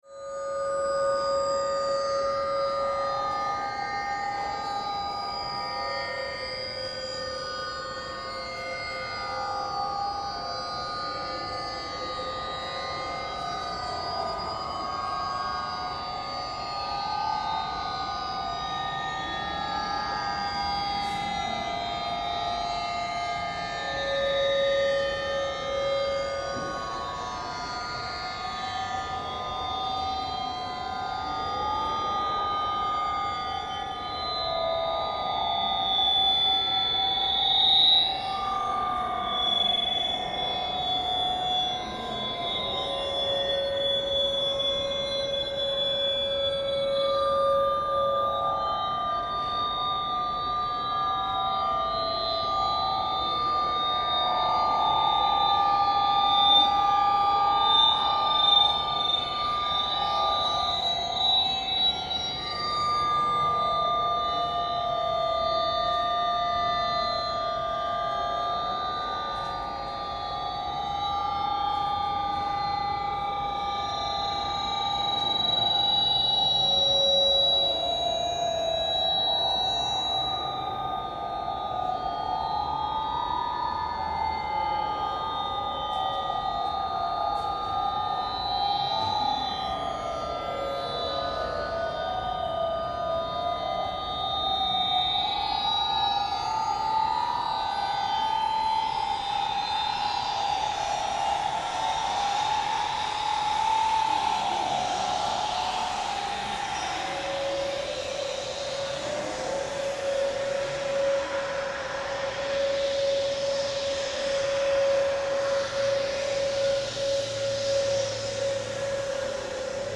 for two laptops
Recording of one of the performances